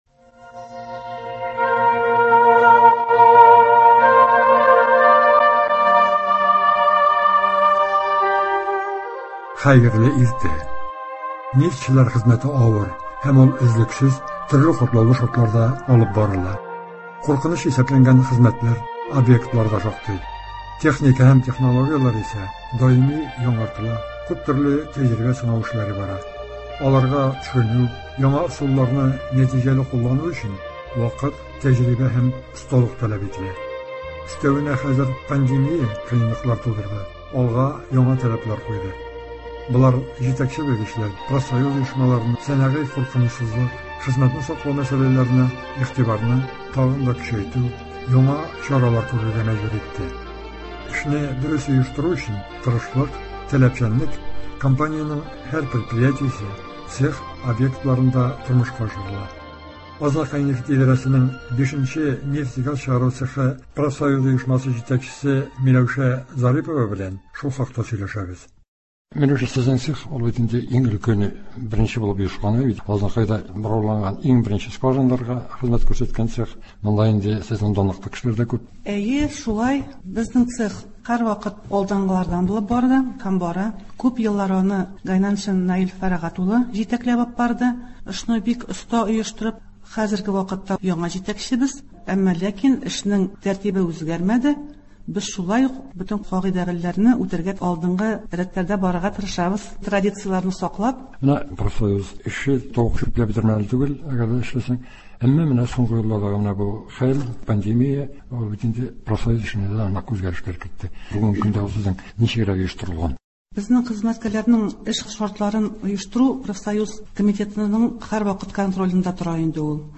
әңгәмә.